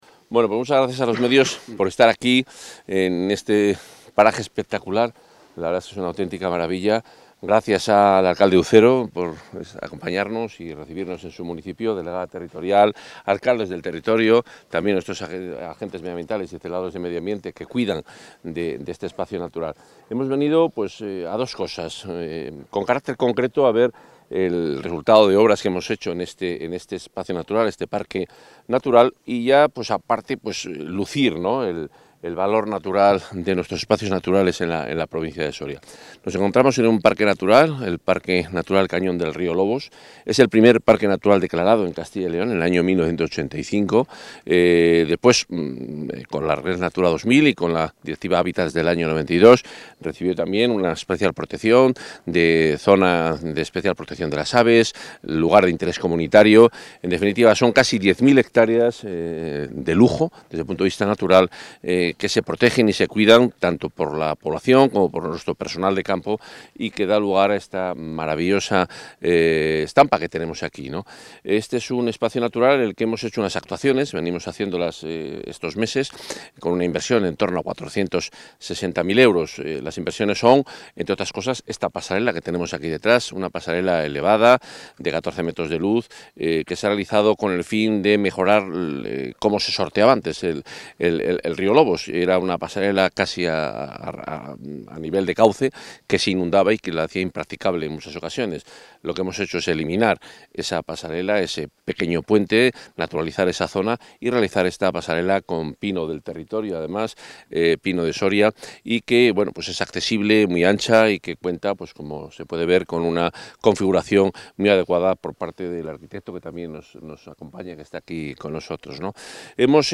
Intervención del consejero.
El consejero de Medio Ambiente, Vivienda y Ordenación del Territorio, Juan Carlos Suárez-Quiñones, ha visitado esta mañana el Parque Natural del Cañón del Río Lobos, en la localidad soriana de Ucero, donde ha comprobado el buen estado general de este espacio natural, en el que la Junta ha reforzado recientemente sus infraestructuras con nuevas intervenciones que han supuesto una inversión de 468.381 euros.